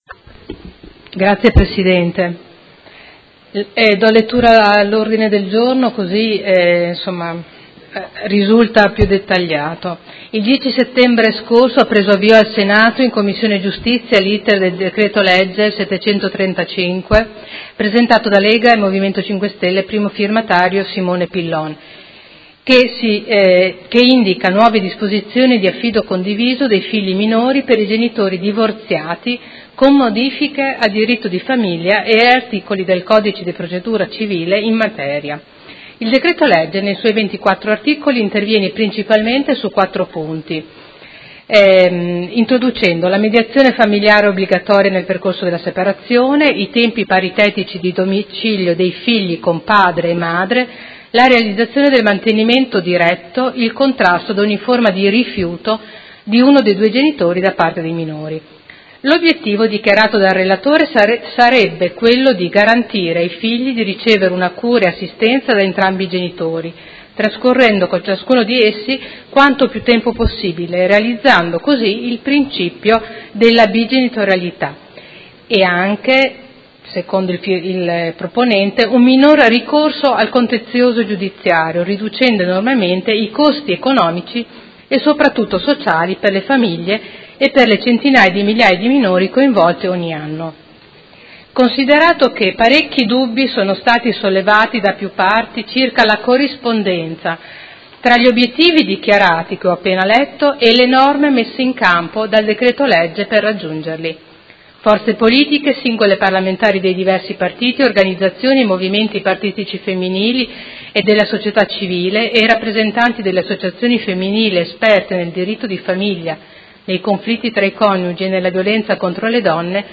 Caterina Liotti — Sito Audio Consiglio Comunale